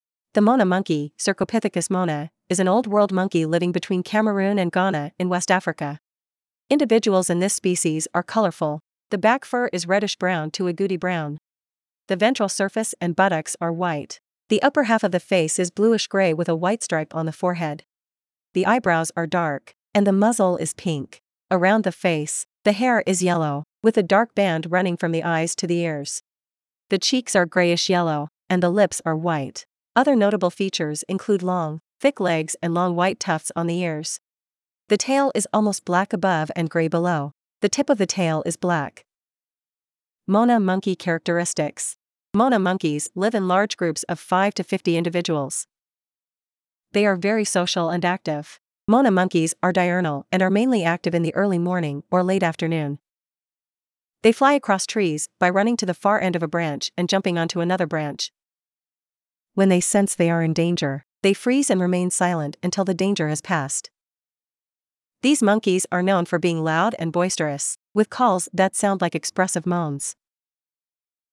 • These monkeys are known for being loud and boisterous, with calls that sound like expressive moans.
Mona-monkeys.mp3